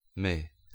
Ääntäminen
IPA : /wiː/ : IPA : /wi/ US : IPA : [wi] UK : IPA : [wiː]